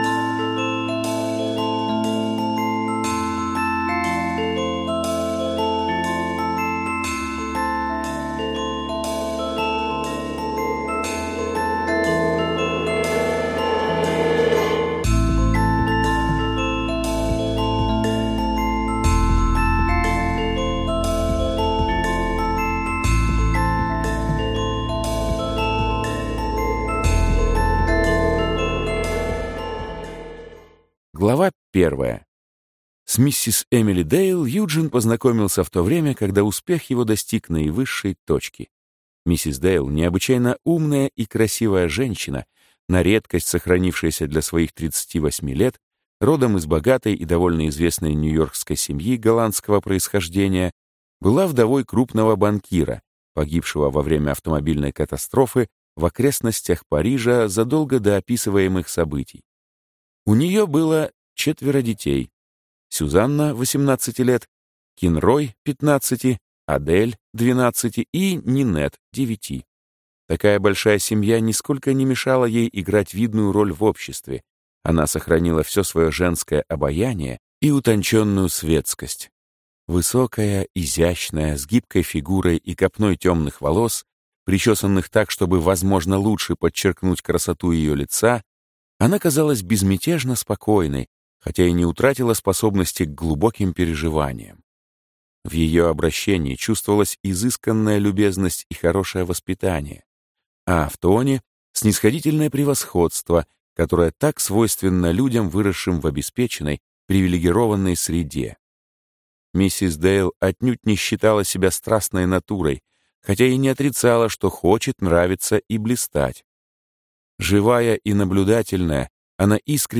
Аудиокнига Гений. Книга 3 | Библиотека аудиокниг